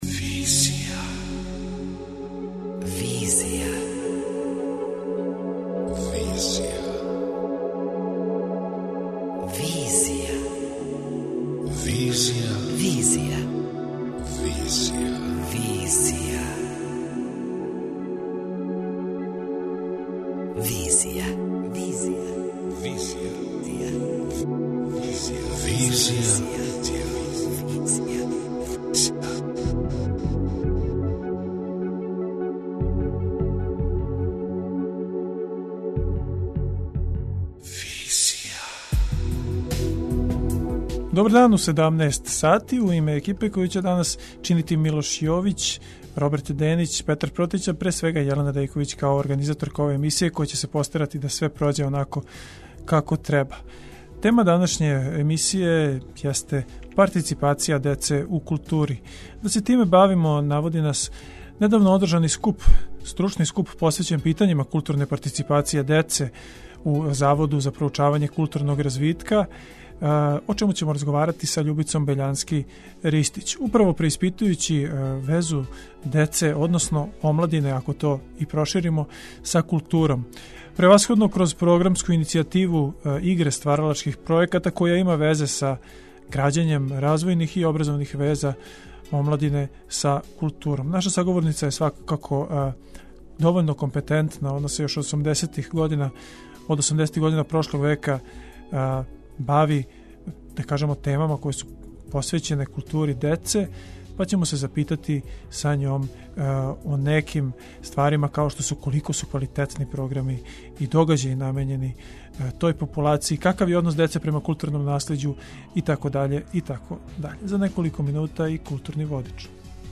преузми : 28.22 MB Визија Autor: Београд 202 Социо-културолошки магазин, који прати савремене друштвене феномене.